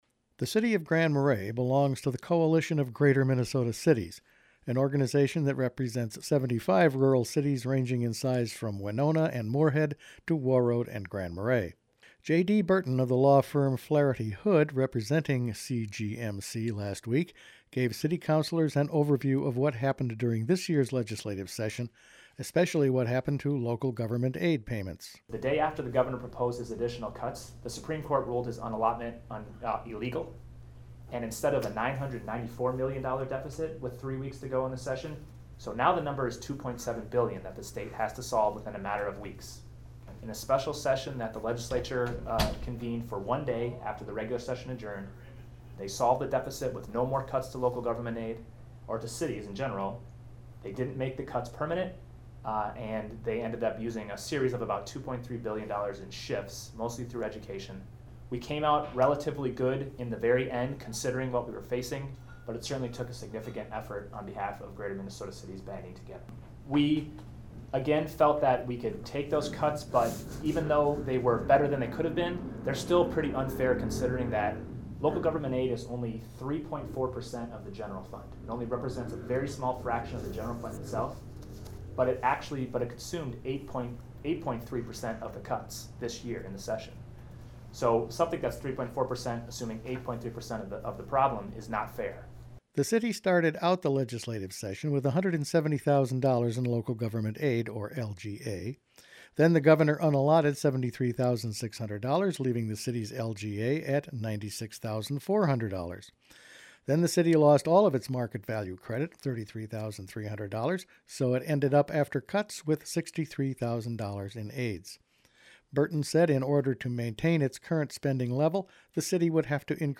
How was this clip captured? Local Government Aid woes explored at City meeting